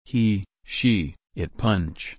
/pʌntʃt/